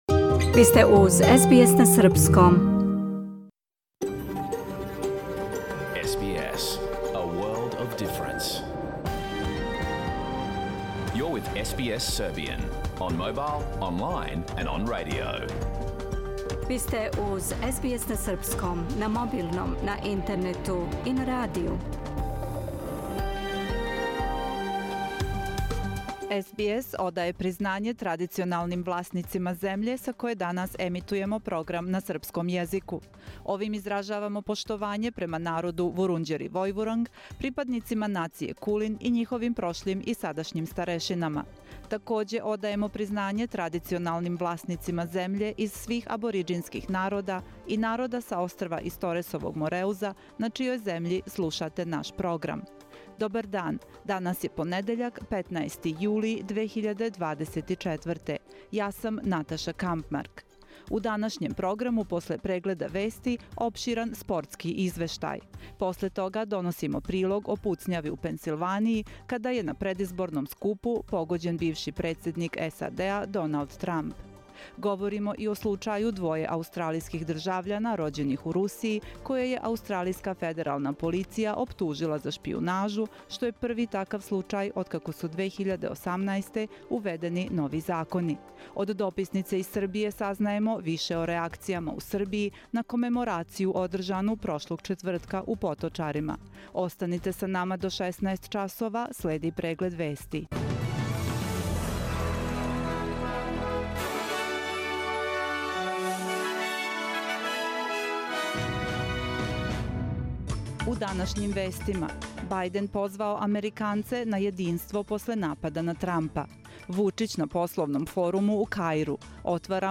Програм емитован уживо 15. јула 2024. године
Уколико сте пропустили данашњу емисију, можете је послушати у целини као подкаст, без реклама.